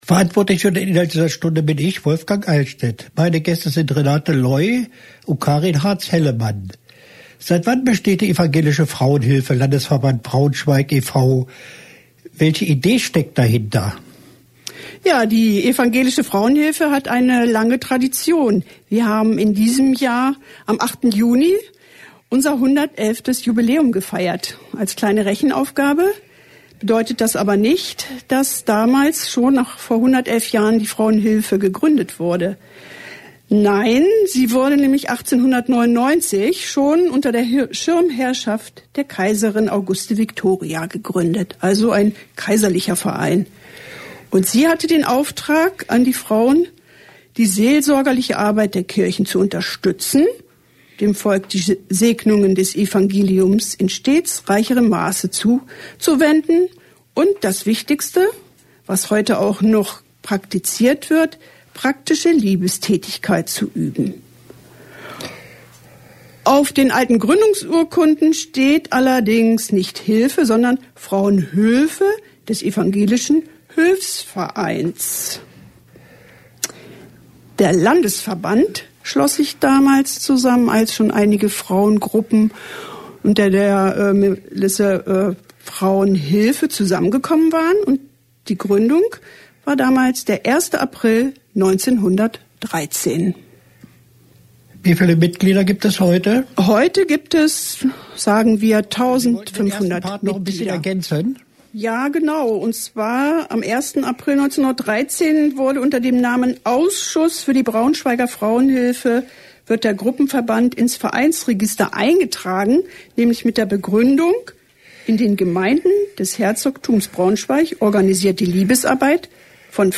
Jeden Donnerstag wird die Wunschkiste live auf Sendung gebracht.
Die etwas harten Übergänge sind durch das Entfernen der Lieder entstanden.